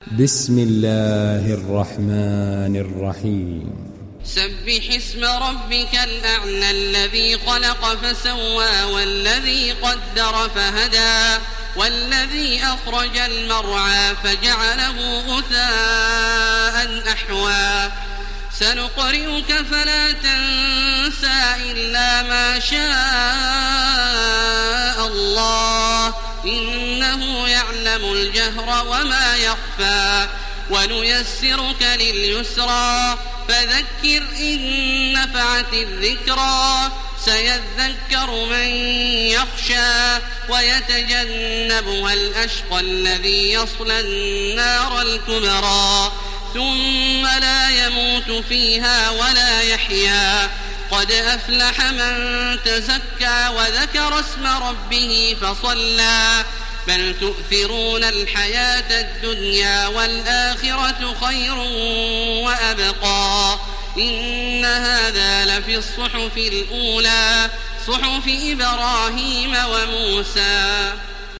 تحميل سورة الأعلى mp3 بصوت تراويح الحرم المكي 1430 برواية حفص عن عاصم, تحميل استماع القرآن الكريم على الجوال mp3 كاملا بروابط مباشرة وسريعة
تحميل سورة الأعلى تراويح الحرم المكي 1430